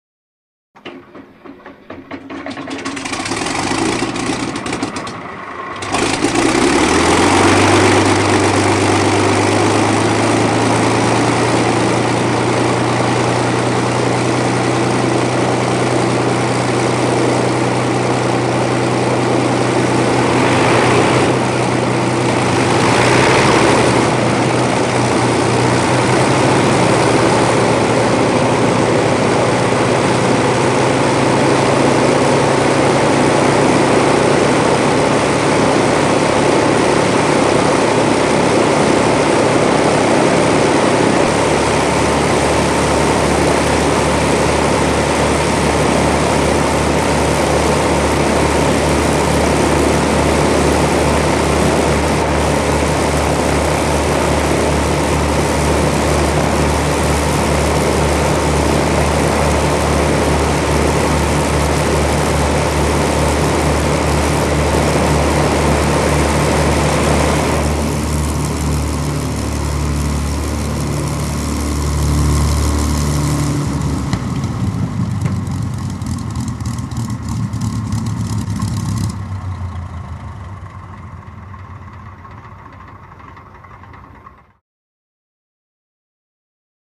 Prop Plane; Start / Idle; Mosquito Prop Aircraft Start Up And Run To Constant, Then Switch Off. Restarts Engine One, Then Engine Two And Both Run At Constant, Then Switches Off.